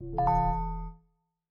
steeltonguedrum_ce.ogg